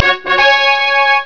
trumpet.au